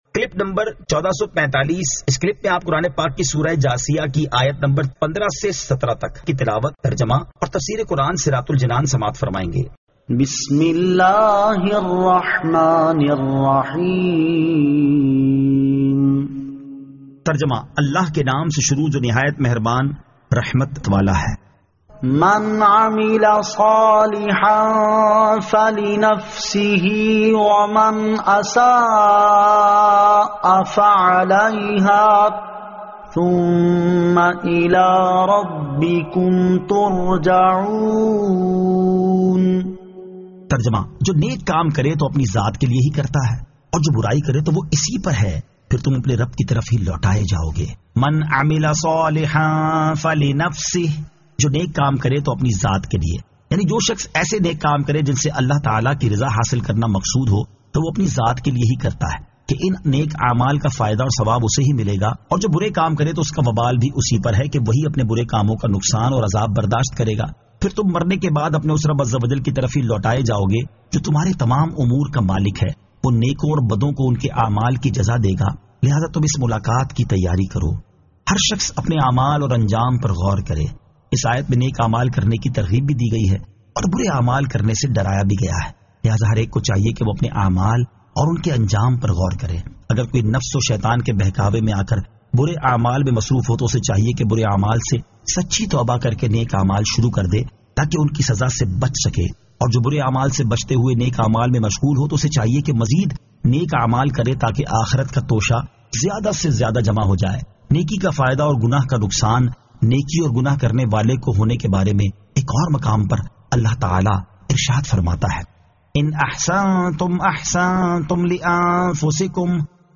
Surah Al-Jathiyah 15 To 17 Tilawat , Tarjama , Tafseer
2023 MP3 MP4 MP4 Share سُوَّرۃُ الجَاٗثِیَۃ آیت 15 تا 17 تلاوت ، ترجمہ ، تفسیر ۔